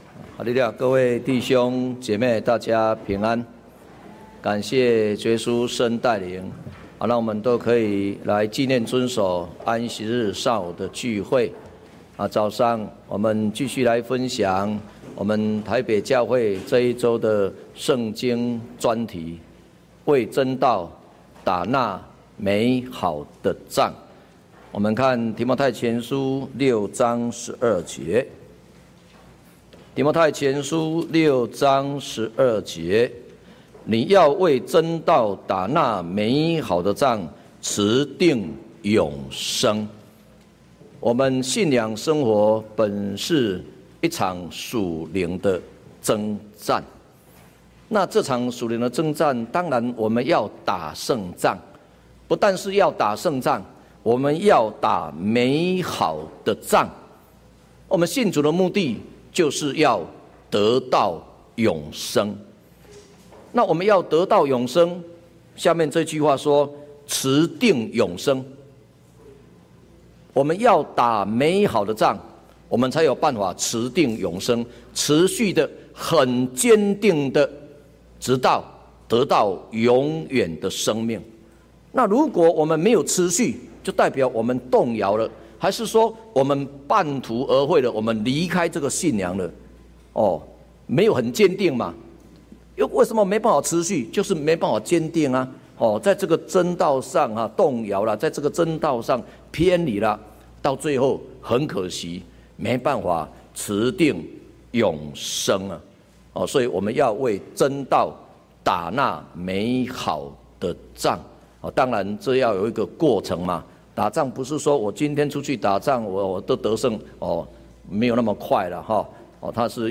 專題講座：為真道打那美好的仗 _固守真道-講道錄音